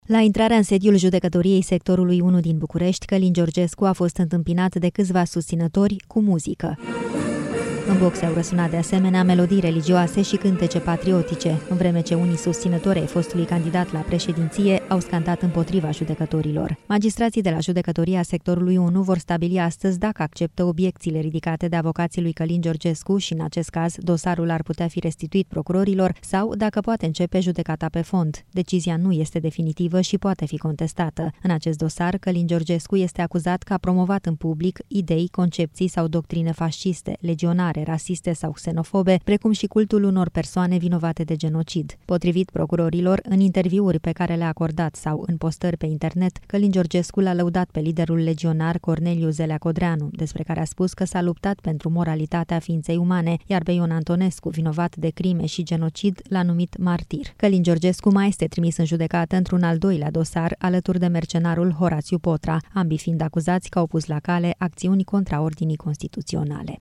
La intrarea în sediul Judecătoriei Sectorului 1 din București, Călin Georgescu a fost întâmpinat de câțiva susținători cu muzică.
În boxe, au răsunat de asemenea melodii religioase și cântece patriotice, în vreme ce unii susținători ai fostului candidat la președinție au scandat împotriva judecătorilor.